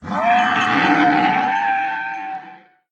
Minecraft Version Minecraft Version latest Latest Release | Latest Snapshot latest / assets / minecraft / sounds / mob / horse / zombie / death.ogg Compare With Compare With Latest Release | Latest Snapshot
death.ogg